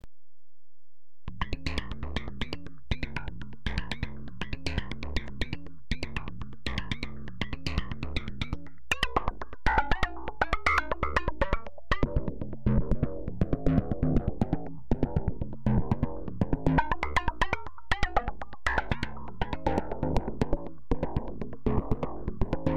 Soft Synth